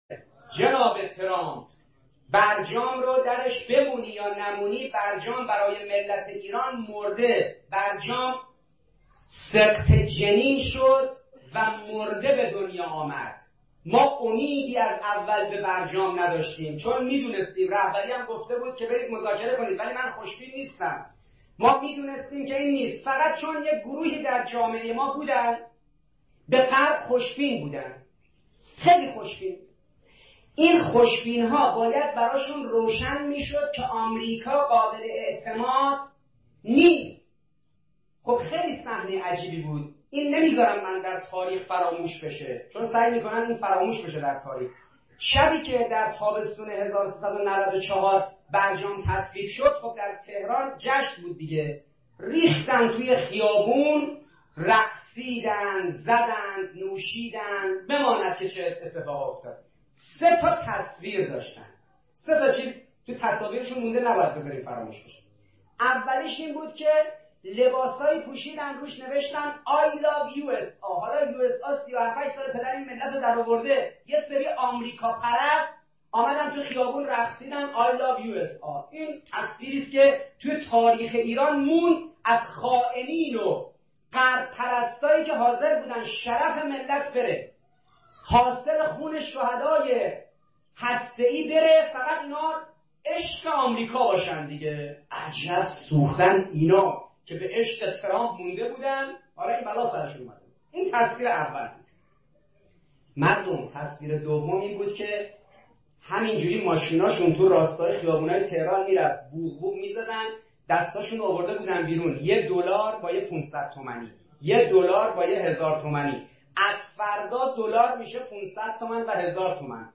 1 آخرین مطالب موسیقی دکتر حسن عباسی سخنرانی سخنرانی